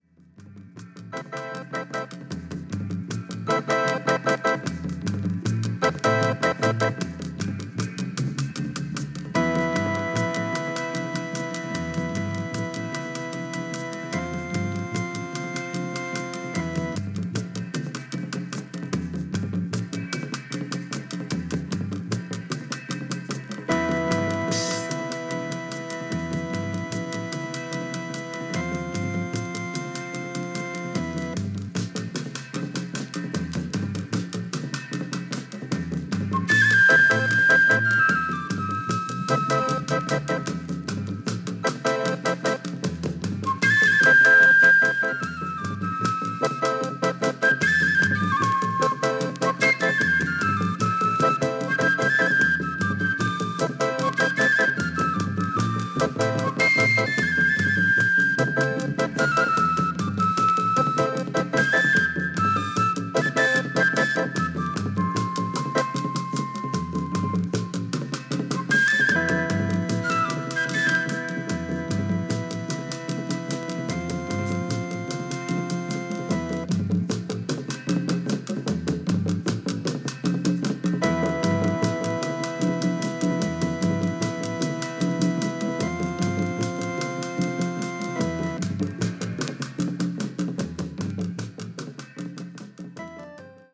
ガーナ・アフロバンド